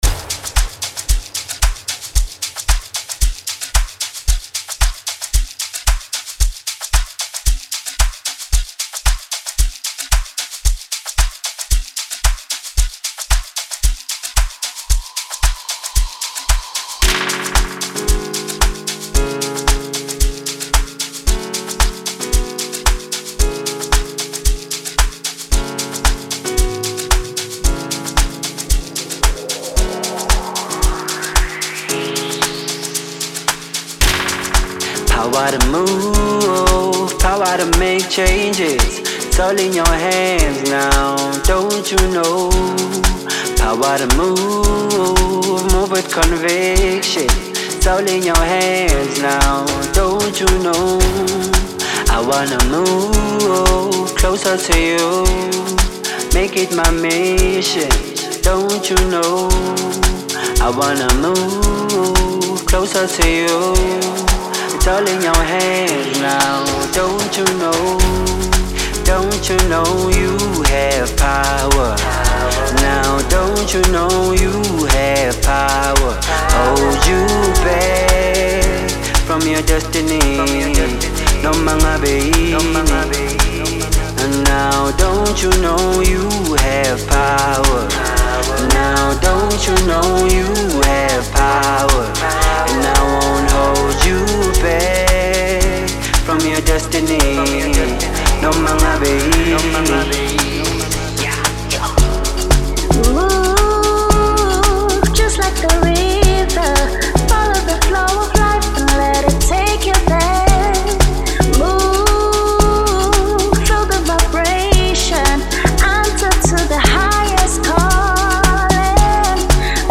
soothing song